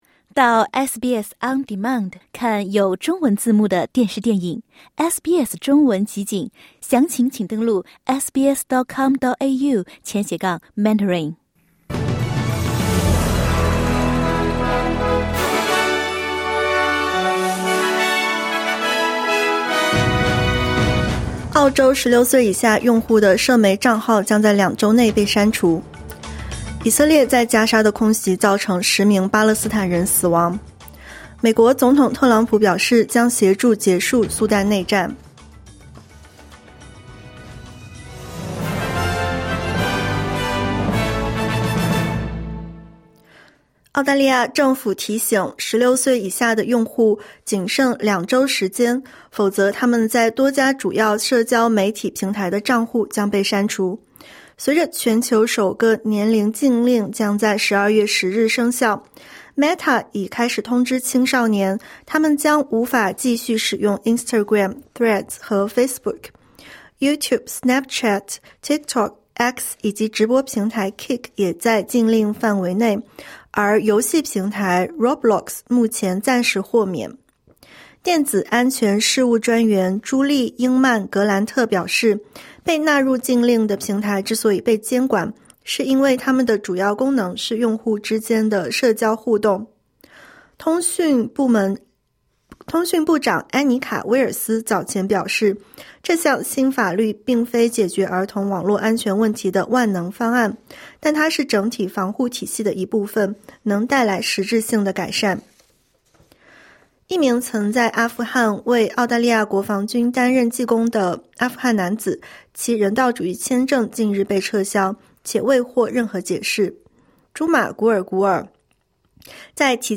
SBS 早新闻（2025年11月20日）